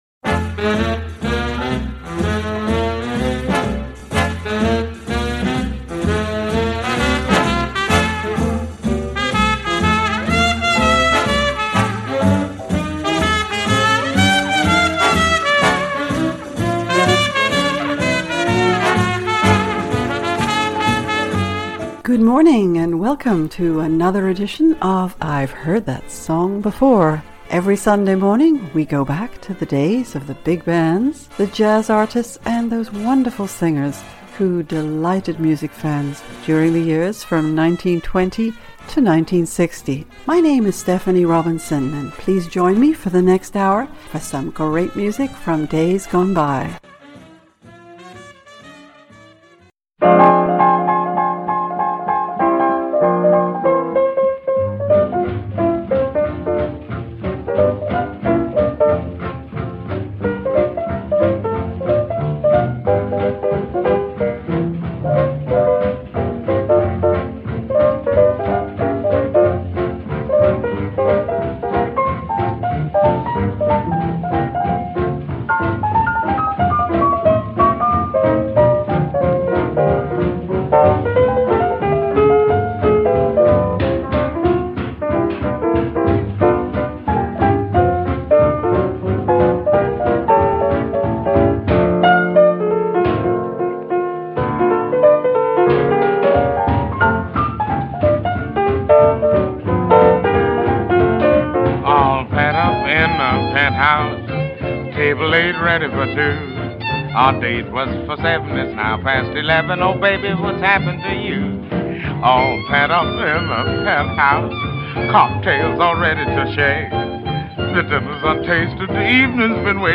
big bands
small groups